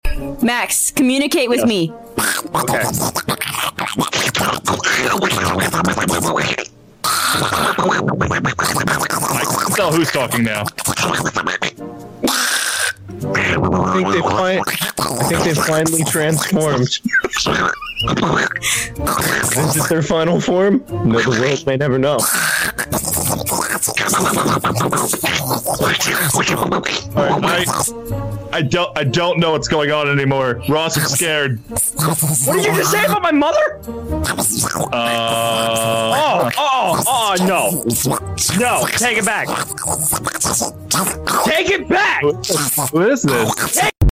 GREMLIN NOISES (Sound from, Aphmau sound effects free download